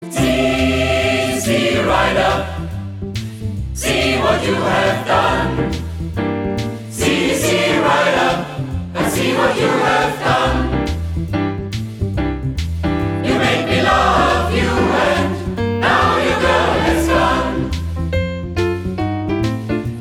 Rock’n’Roll